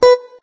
note_beepey_7.ogg